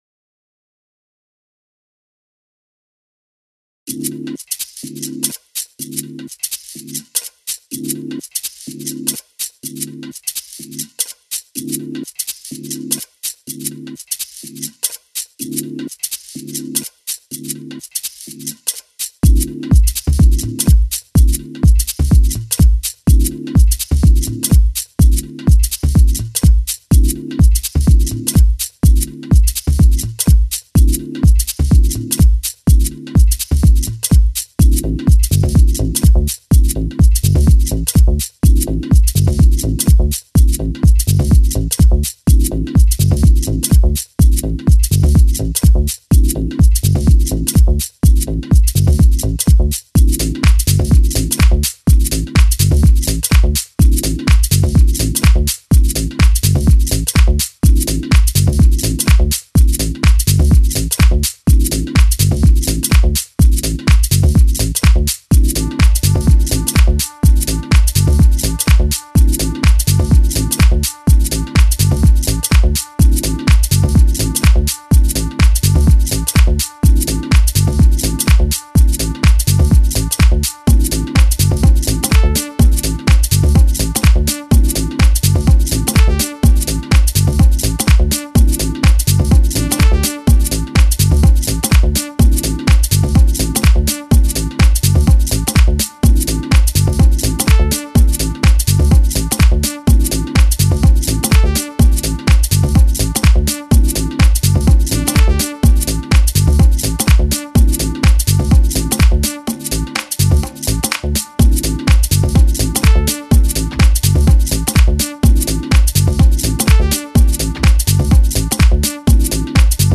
dance/electronic
House
Electro